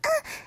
moan1.ogg